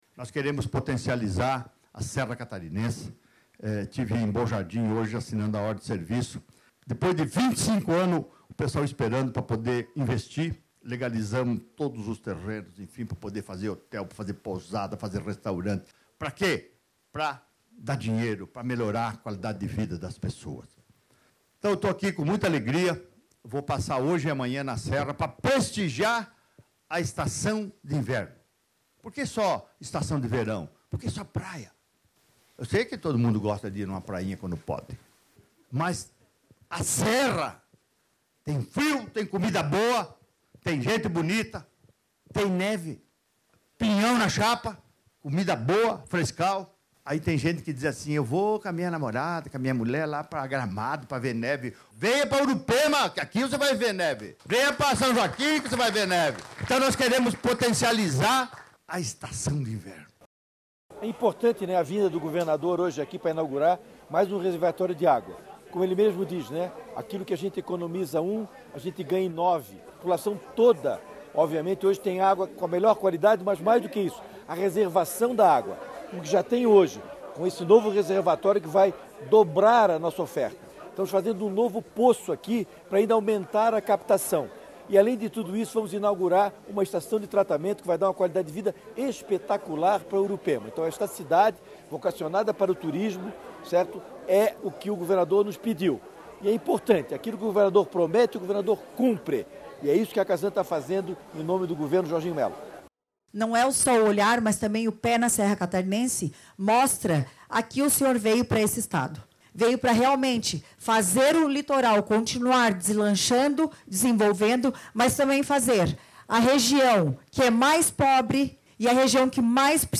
Durante a passagem pela região, o governador ressaltou mais uma vez o trabalho de fomentar o turismo de inverno catarinense:
SECOM-Sonoras-Inaugura-novo-reservatorio-da-Casan-em-Urupema-1.mp3